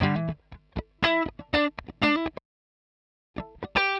120_Guitar_funky_riff_E_6.wav